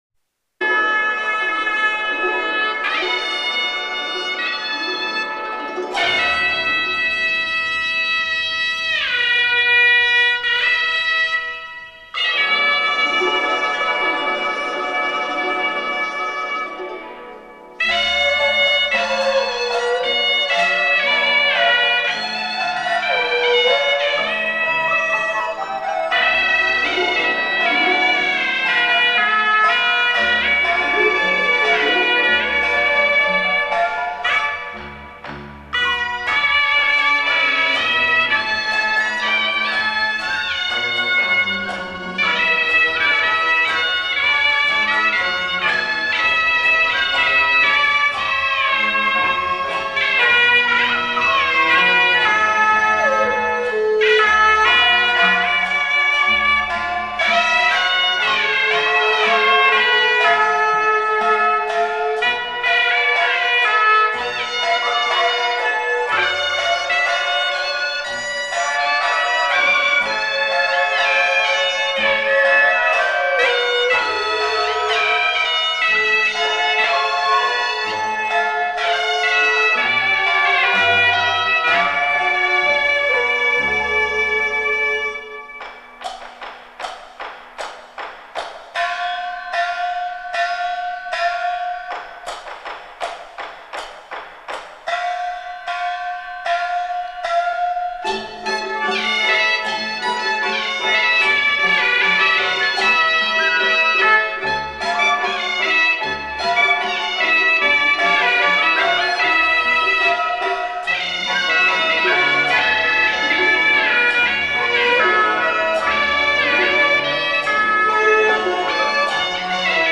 著名唢呐演奏家
他的演奏醇厚圆润，音色坚实柔美，刚柔并济，误区能够气势宏伟 磅撙，强而不蹀，文曲则能够细腻深沉，韵味隽永，柔而不虚。